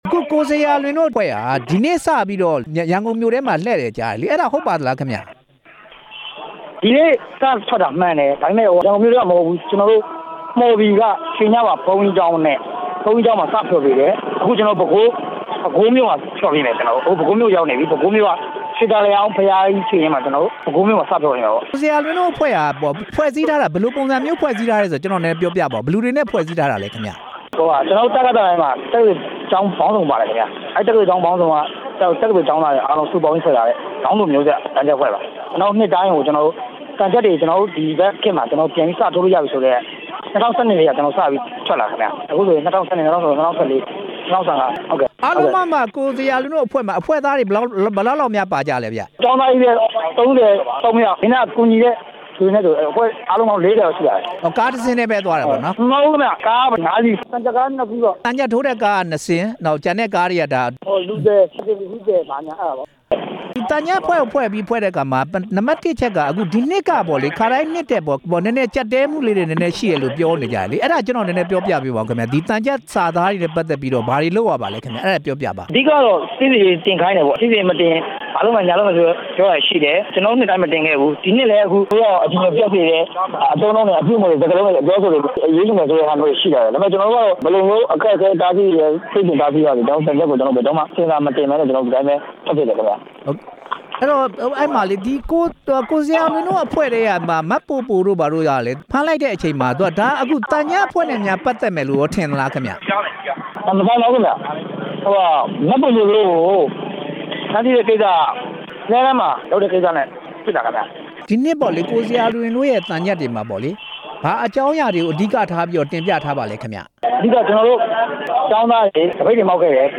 ဒေါင်းမျိုးဆက် သံချပ်အဖွဲ့ကို မေးမြန်းချက်